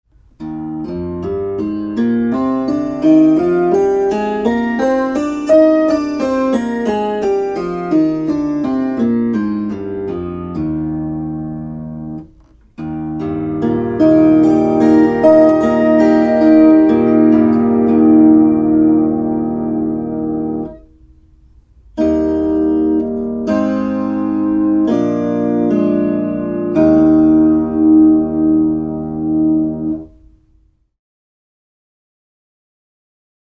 Marsilaiset marssivat e-, a ja h-vivut kumoon. Kuuntele Es-duuri. b as es Opettele duurit C G D A E F B As mollit a e h fis cis d g c f Tästä pääset harjoittelun etusivulle.